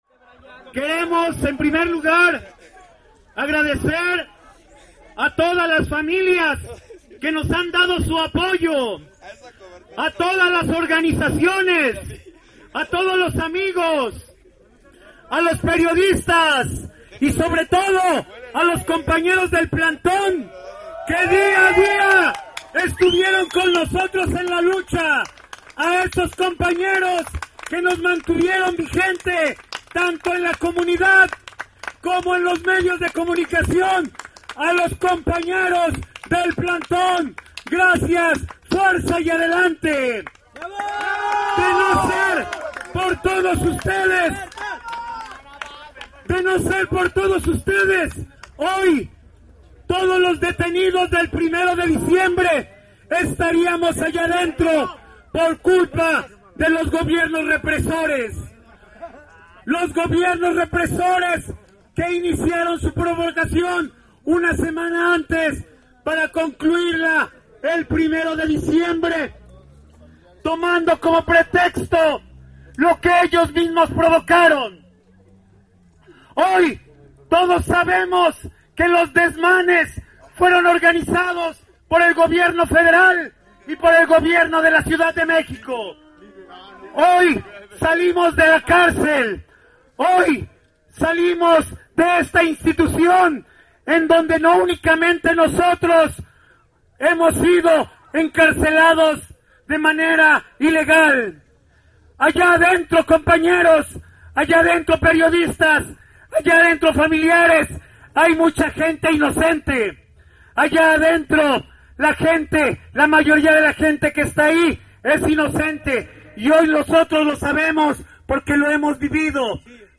Minutos más tarde dio inicio un acto donde “La Liga de Abogados 1 de Diciembre” y los ahora ex Presos Políticos, darían sus respectivos pronunciamientos.
Participación de un ex Presos Político